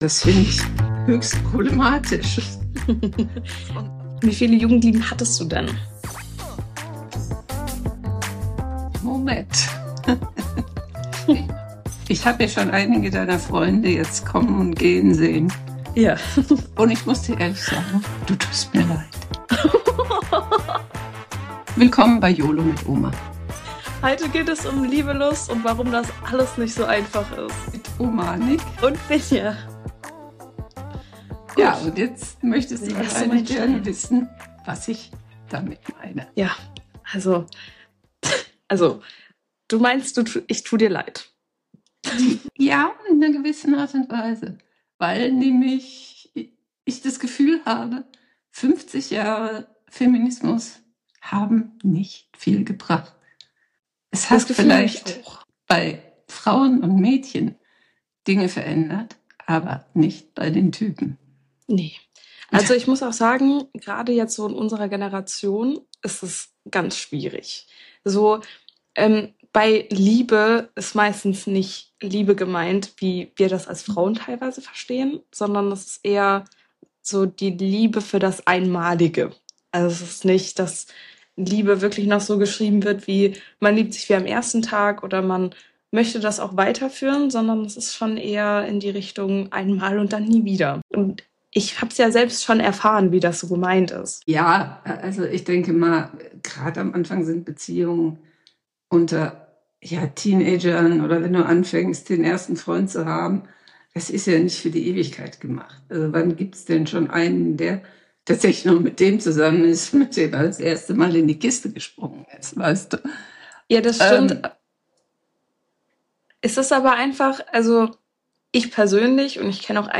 damals und heute in der Generation Z. Es geht um: erste Liebe, emotionale Abhängigkeit, toxische Beziehungen, Freundschaften, Feminismus und warum Kommunikation wichtiger ist als alles andere. Zwei Generationen, ein ehrlicher Schlagabtausch – für alle, die wissen wollen, ob sich Liebe wirklich verändert hat.